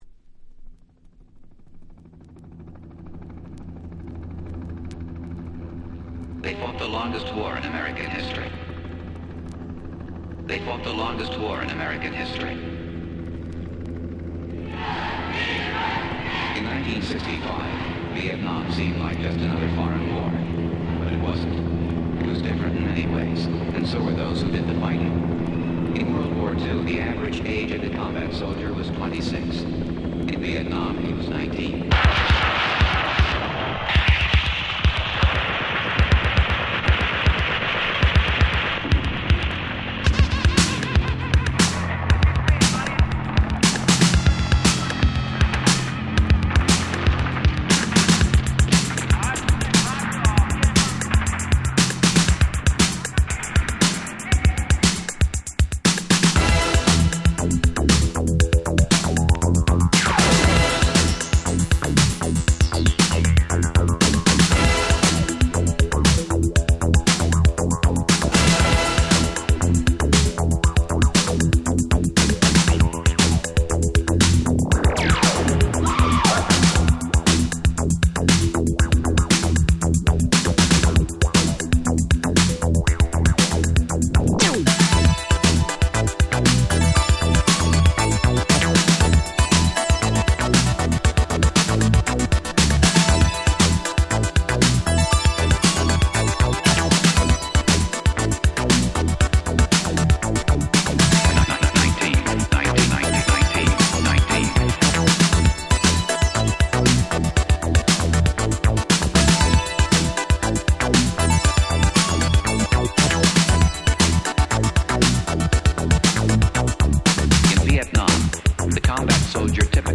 【Media】Vinyl 12'' Single
※一部試聴ファイルは別の盤から録音してございます。
85' Electro Old School 大名曲。
エレクトロ オールドスクール 80's